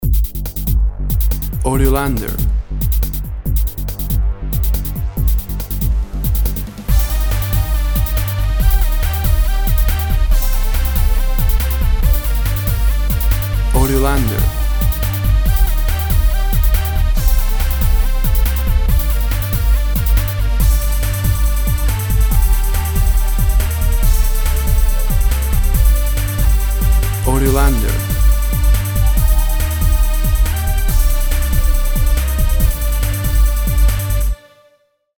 Party-like electronic short song.
Tempo (BPM) 140